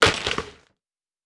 Net Swoosh Intense.wav